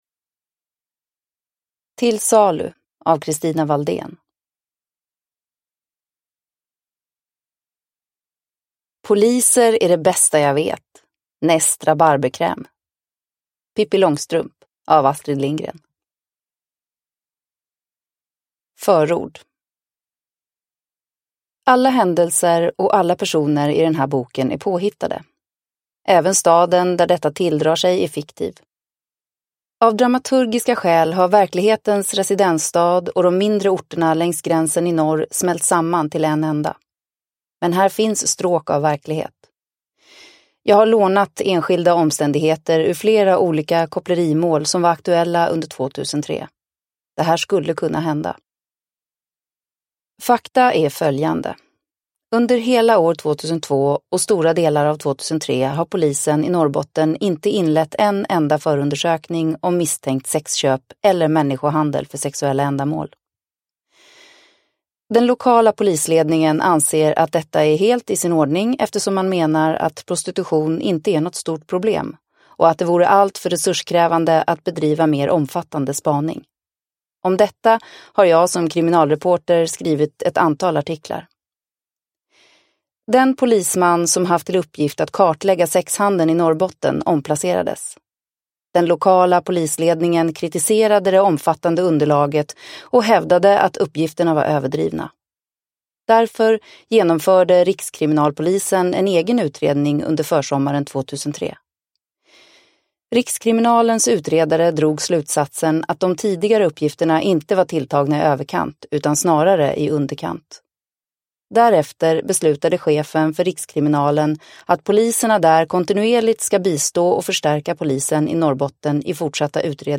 Till salu – Ljudbok – Laddas ner